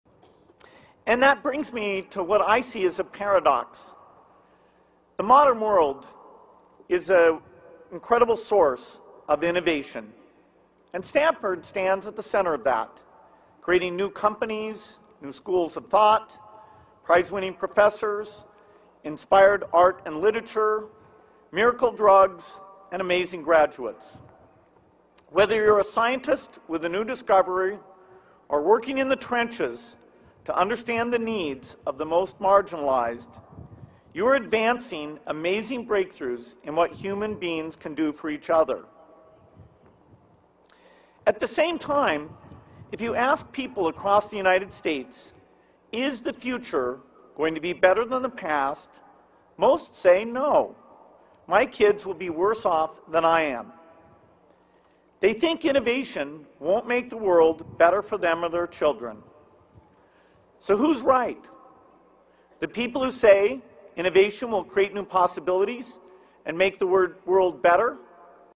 公众人物毕业演讲第35期:比尔盖茨夫妇于斯坦福大学(16) 听力文件下载—在线英语听力室